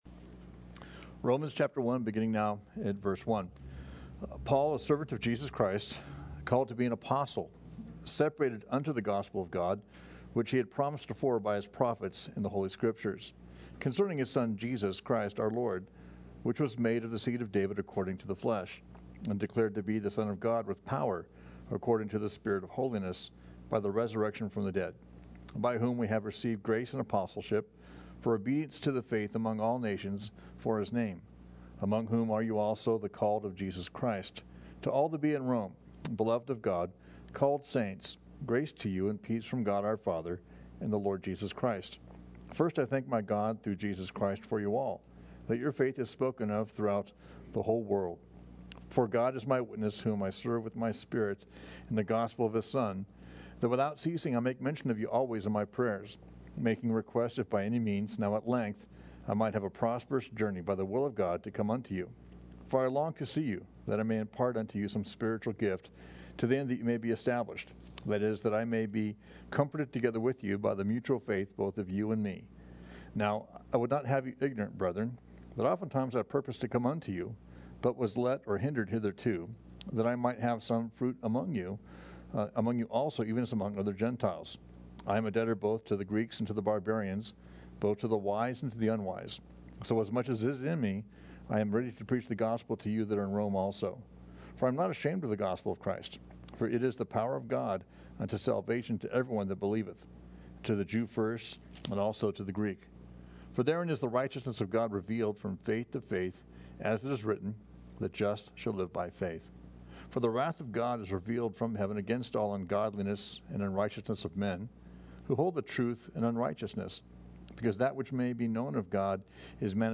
Thank you for checking out our study series.